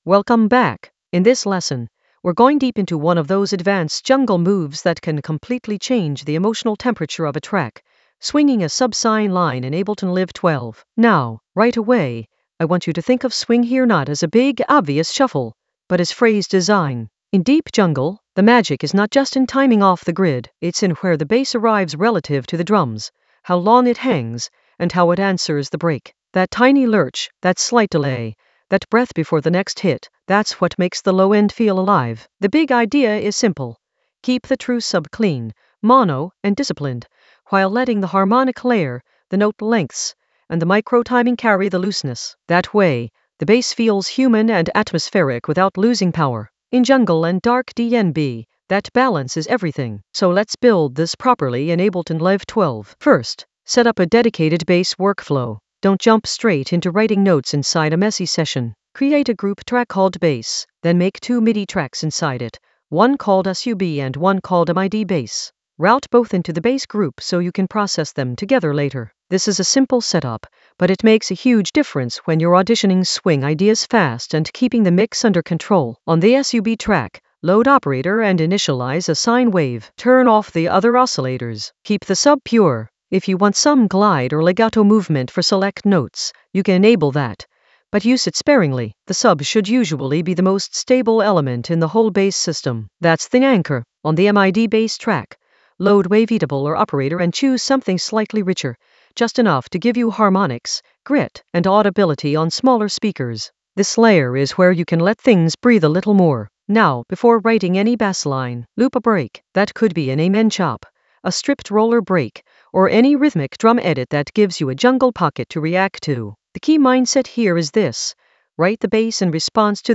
Narrated lesson audio
The voice track includes the tutorial plus extra teacher commentary.
An AI-generated advanced Ableton lesson focused on Swing a subsine for deep jungle atmosphere in Ableton Live 12 in the Workflow area of drum and bass production.